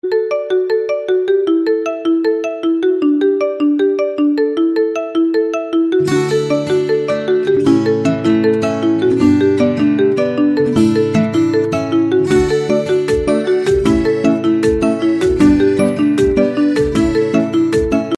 • Качество: высокое